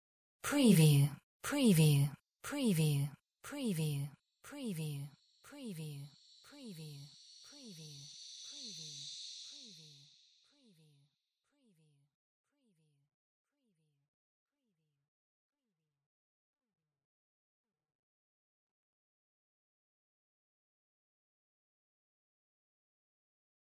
Surreal whisper brittle 01
SCIFI_WHISPERS_BRITTLE_WBSD01
Stereo sound effect - Wav.16 bit/44.1 KHz and Mp3 128 Kbps
previewSCIFI_WHISPERS_BRITTLE_WBHD01.mp3